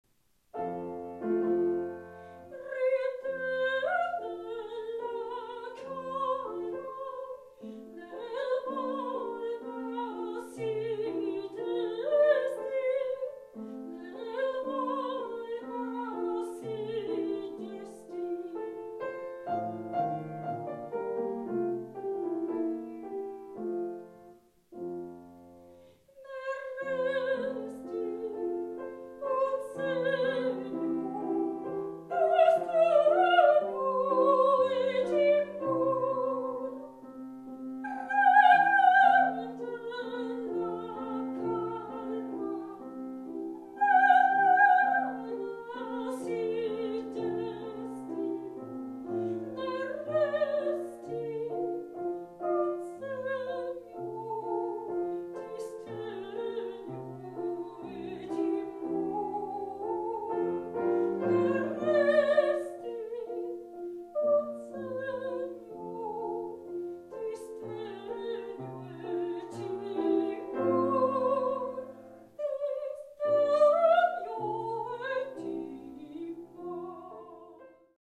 Ich bin Sopranistin und interpretiere sehr gern Klassische Musik, angefangen von Liedern und Arien alter italienischer Meister und Werken von J.S.Bach, G.F.Händel, über Kompositionen von W.A.Mozart, J.Haydn, F.Schubert, J.Brahms, G. Puccini, G.Verdi bis hin zu G.Gershwin - um nur einige Beispiele zu nennen.